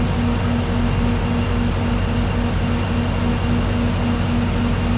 diesel-loop-2.ogg